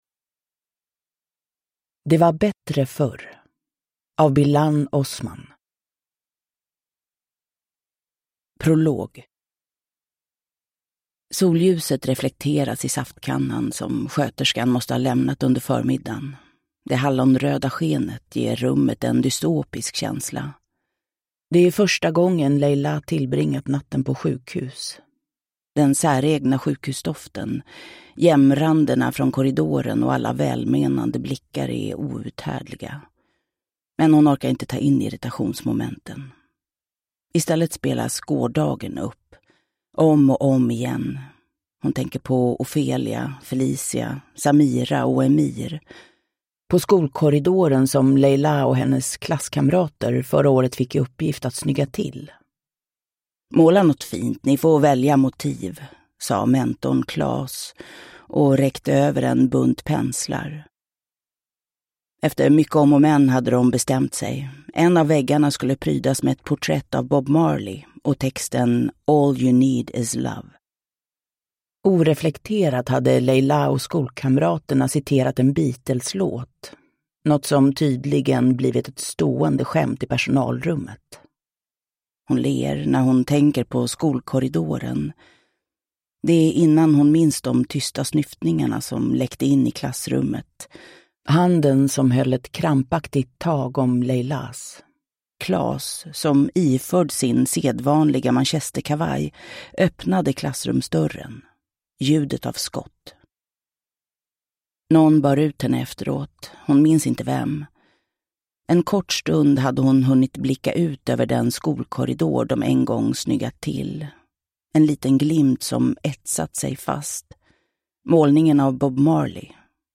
Uppläsare: Jessica Liedberg